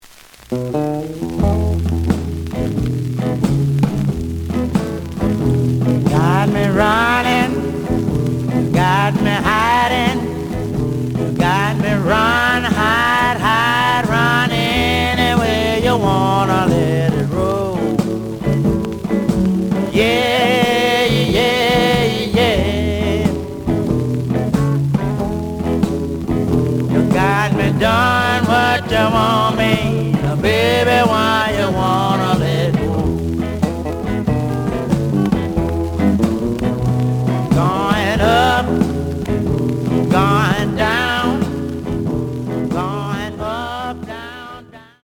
The audio sample is recorded from the actual item.
●Format: 7 inch
●Genre: Rhythm And Blues / Rock 'n' Roll
Some noise on A side.)